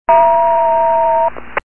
If you spend any time monitoring the Norfolk Southern frequencies in this area you'll get to know this dispatcher tone very well!
ftwaynedisptone.mp3